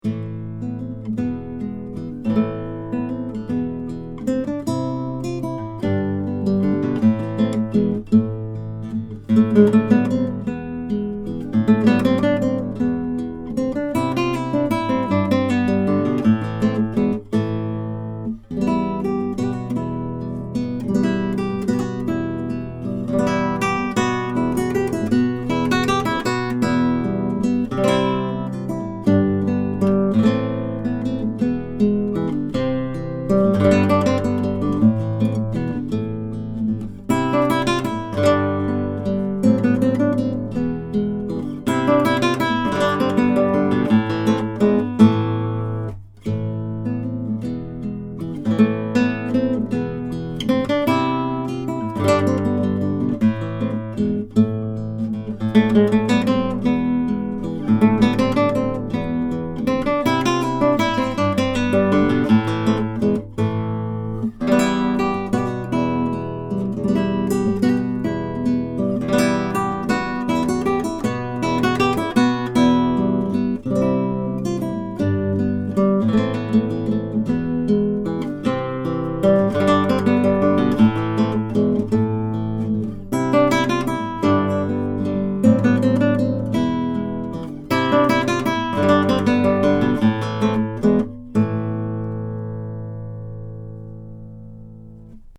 The guitar has a wonderfully sweet sound, with singing trebles and beautiful, deep basses, and excellent resonance and sympathetic sustain.
These MP3 files have no compression, EQ or reverb -- just mic'd through Schoeps and Neumann microphoness, using various patterns, into a Presonus ADL 600 preamp into a Rosetta 200 A/D converter.
NEUMANN KM85 / KK83 OMNI